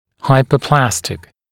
[ˌhaɪpə’plæstɪk][ˌхайпэˈплэстик]гиперплазированный